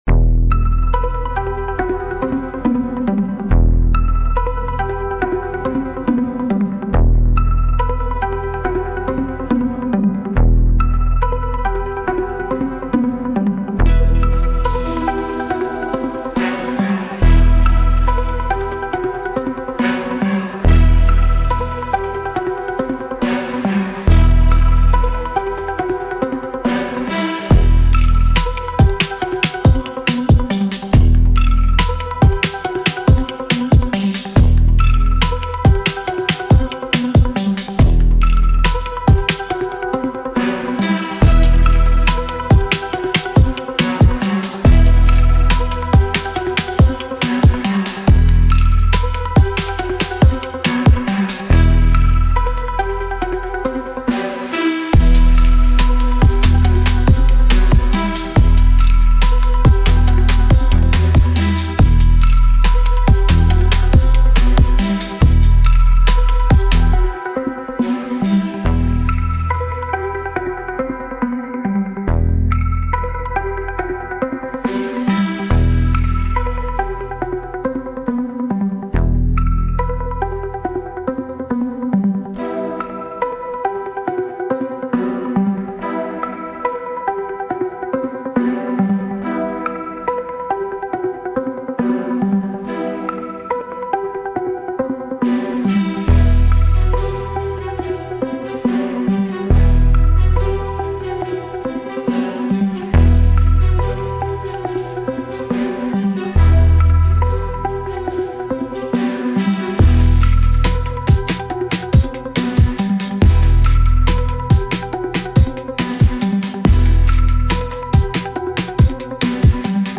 Instrumental - The Streamer’s Blade